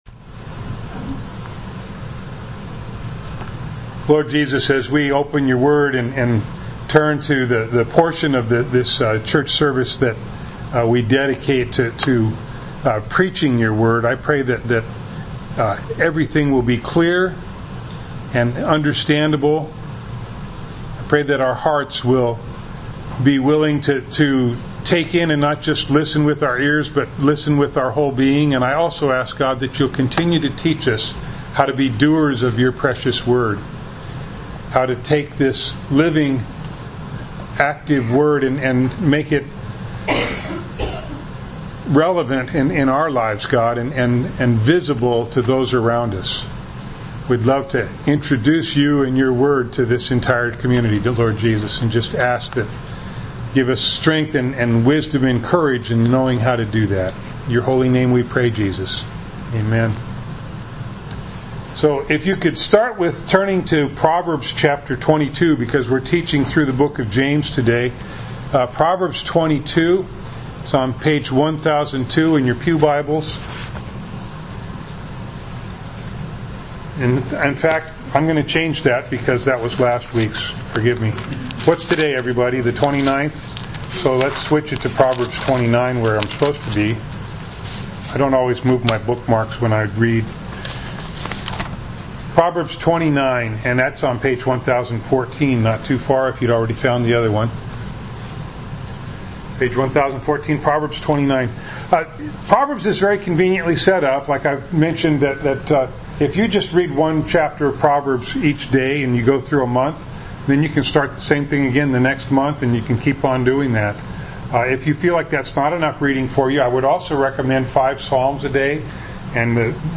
Passage: James 2:5 Service Type: Sunday Morning